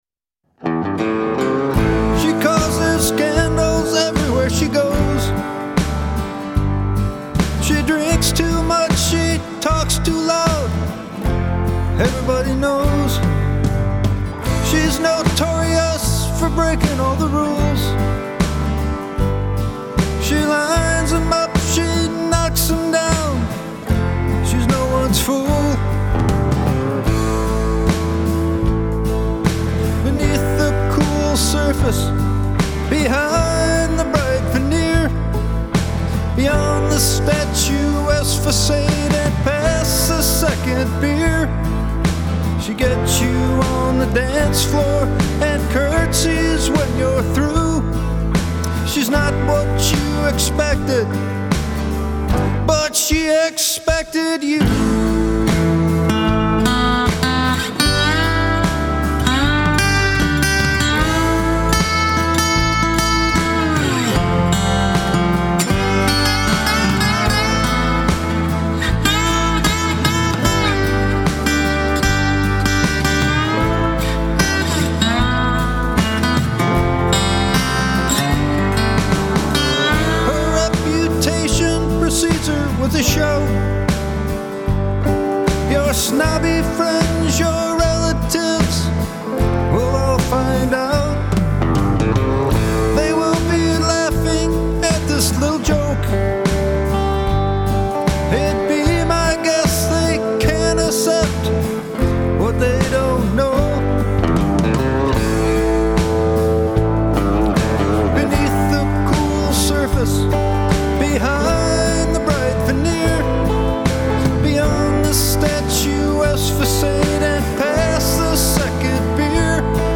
vocal & guitar
harmony and bass.